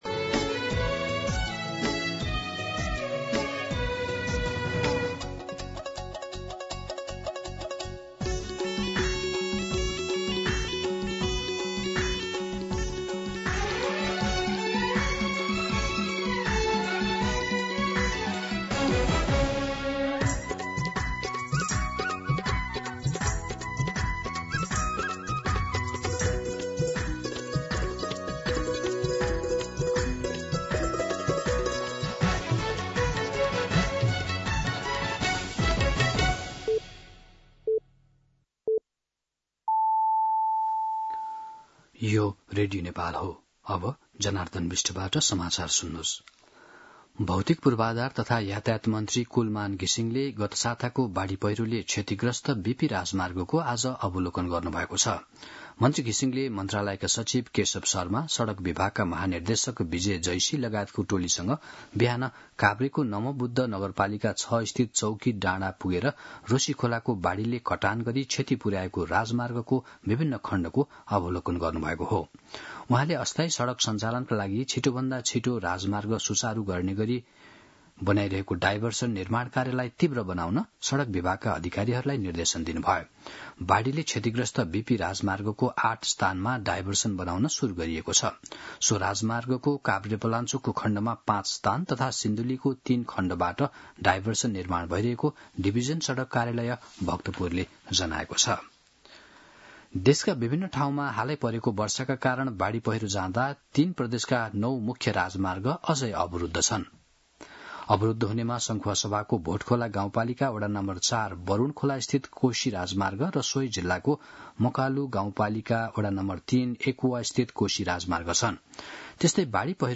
दिउँसो १ बजेको नेपाली समाचार : २३ असोज , २०८२